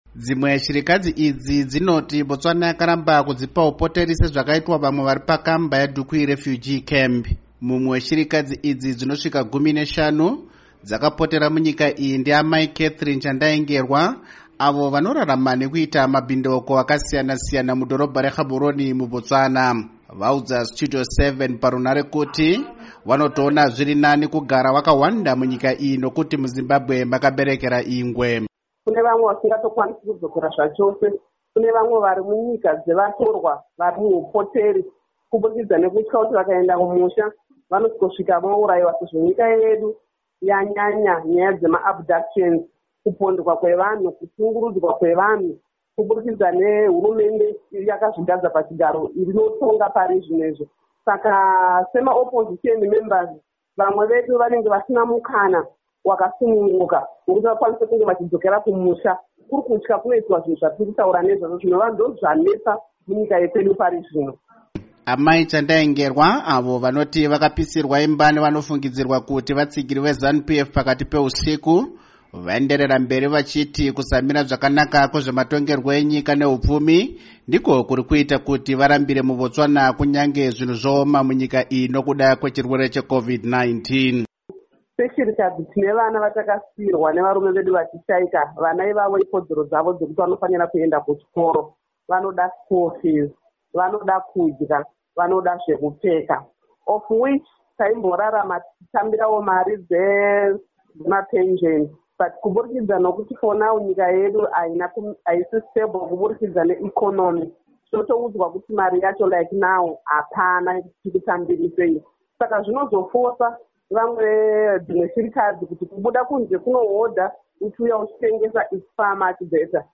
Vaudza Studio 7 parunhare kuti vanotoona zviri nani kugara vakahwanda munyika iyi nokuti muZimbabwe makaberekera ingwe.